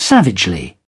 Транскрипция и произношение слова "savagely" в британском и американском вариантах.